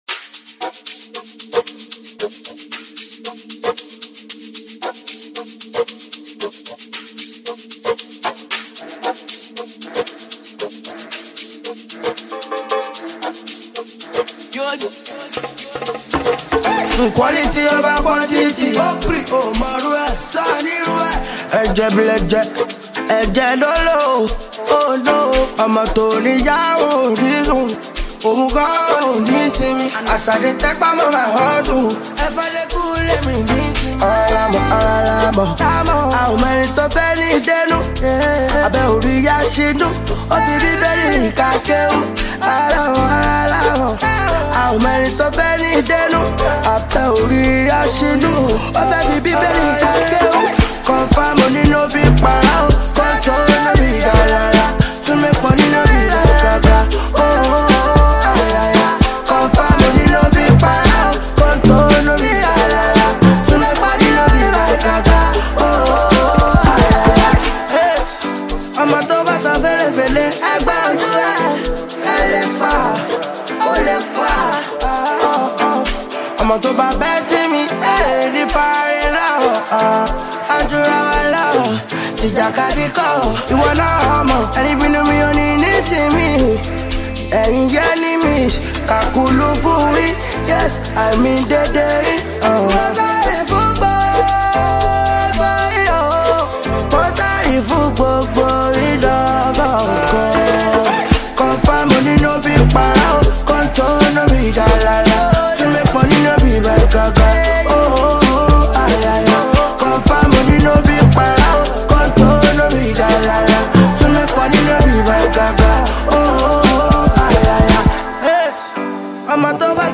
With its infectious melody and captivating lyrics